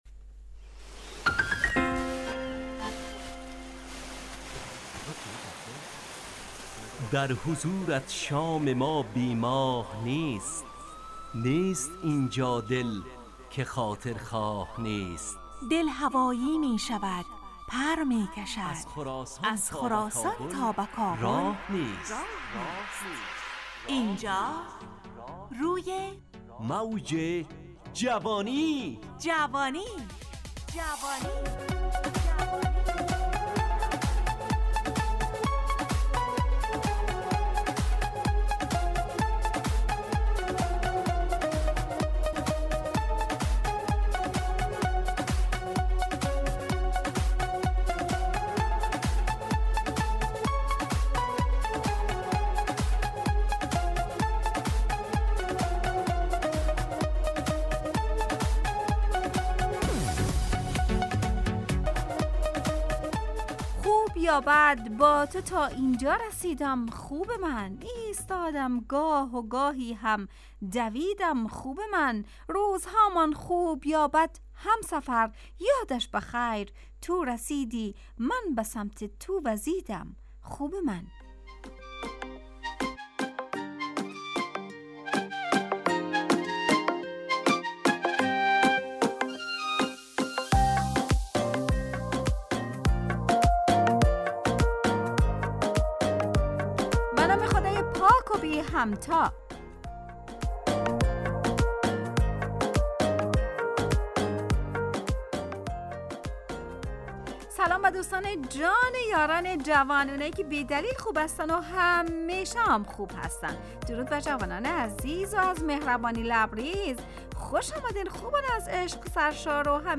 همراه با ترانه و موسیقی مدت برنامه 70 دقیقه . بحث محوری این هفته (خوب و بد)